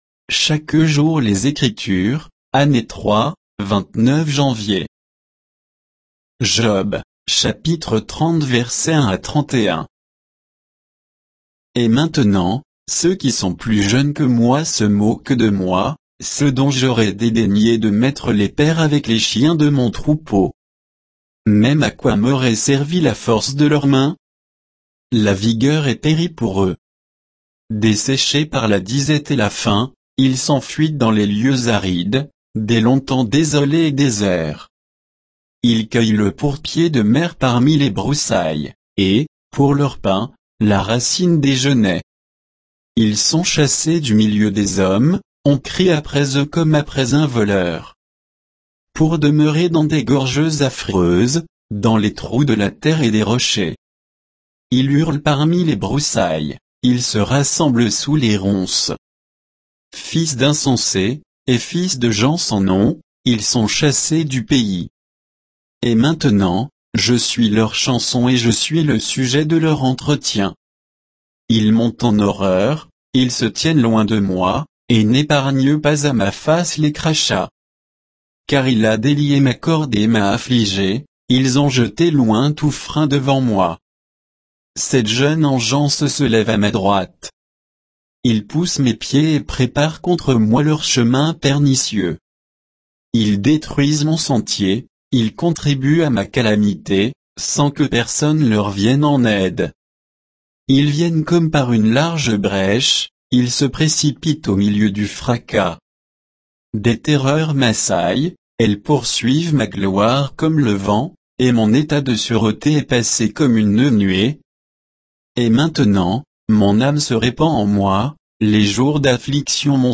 Méditation quoditienne de Chaque jour les Écritures sur Job 30, 1 à 31